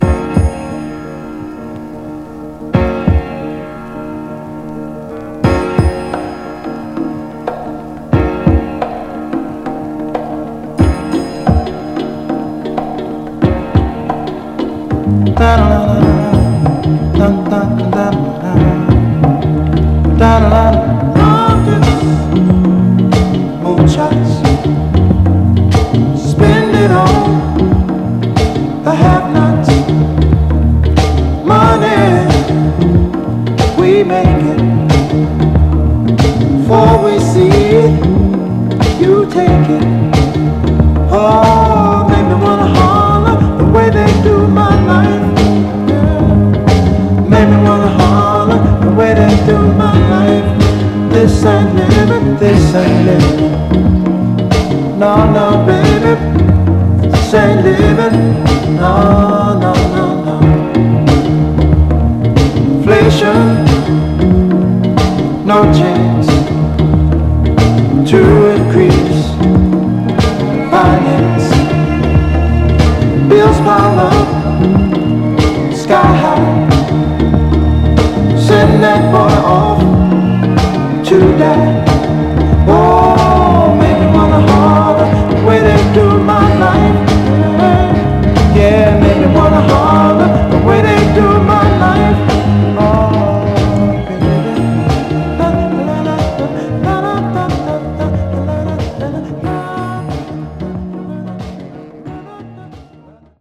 静かなイントロやB面で軽くチリつきますが、目立つノイズは少なく全体的にはプレイ概ね良好です。
※試聴音源は実際にお送りする商品から録音したものです※